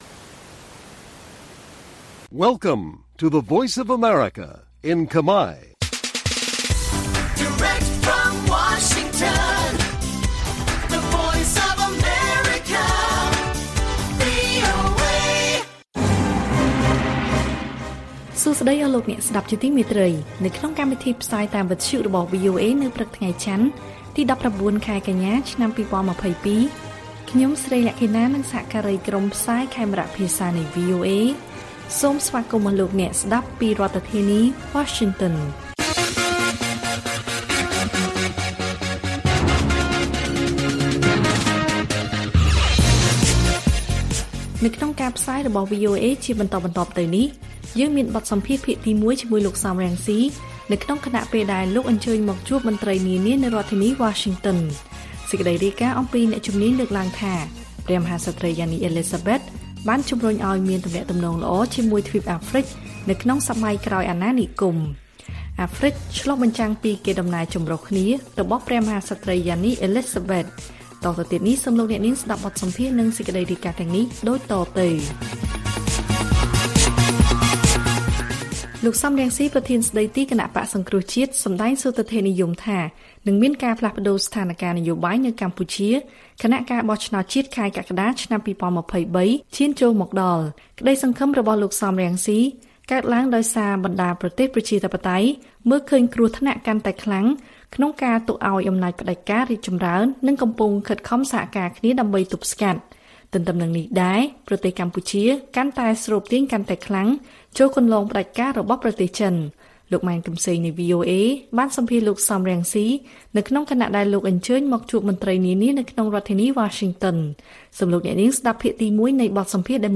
ព័ត៌មានពេលព្រឹក ១៩ កញ្ញា៖ បទសម្ភាសភាគទី១ជាមួយលោក សម រង្ស៊ី ក្នុងខណៈពេលដែលលោកអញ្ជើញមកជួបមន្ត្រីនានានៅរដ្ឋធានីវ៉ាស៊ីនតោន